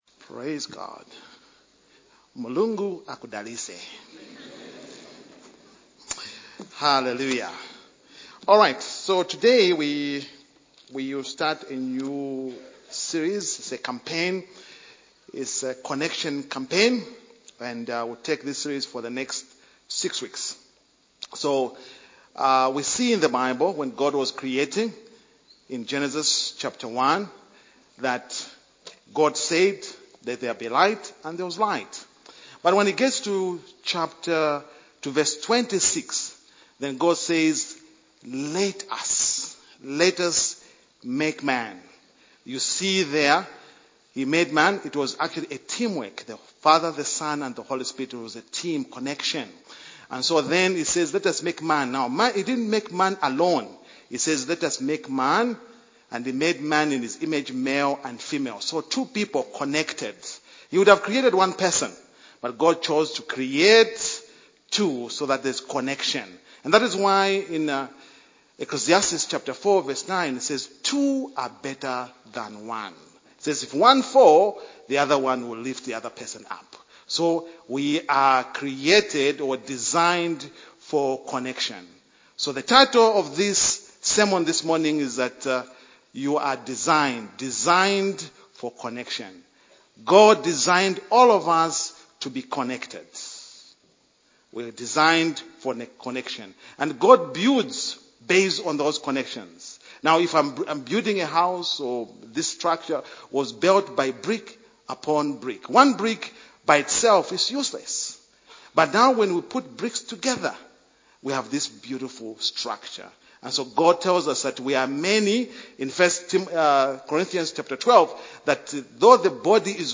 MLFC Sermons